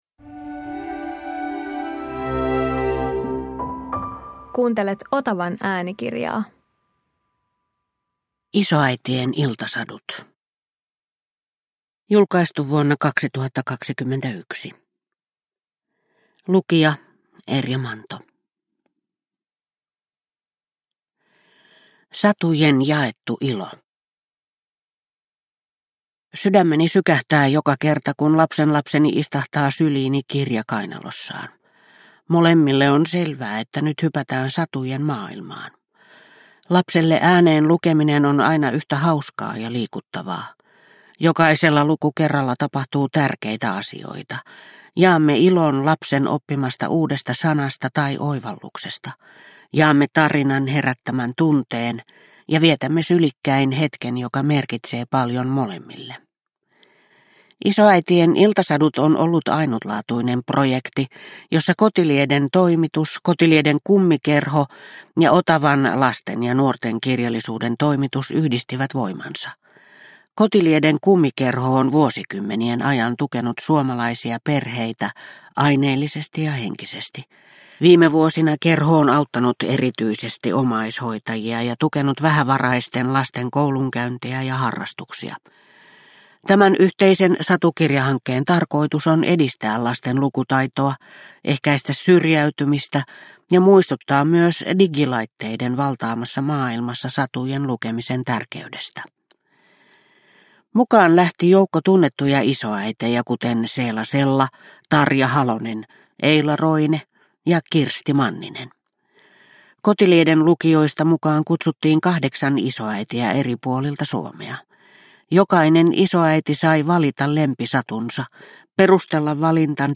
Isoäitien iltasadut – Ljudbok – Laddas ner